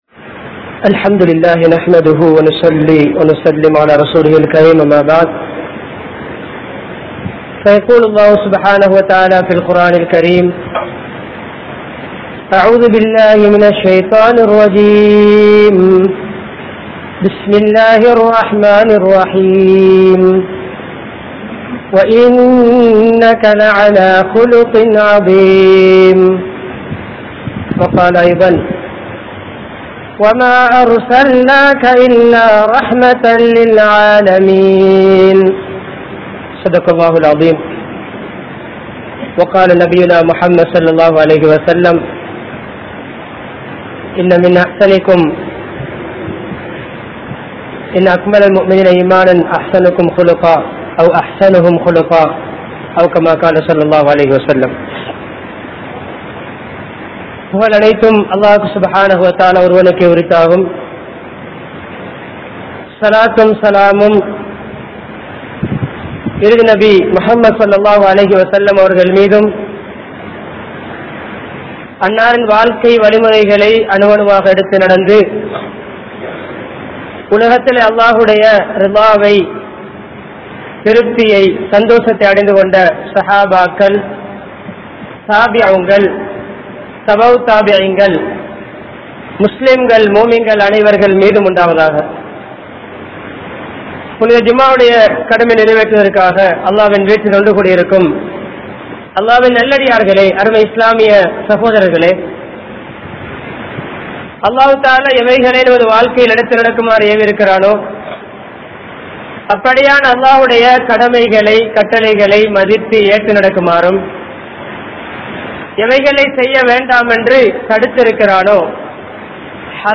Pirarin Ullangalai Veallungal (பிறரின் உள்ளங்களை வெல்லுங்கள்) | Audio Bayans | All Ceylon Muslim Youth Community | Addalaichenai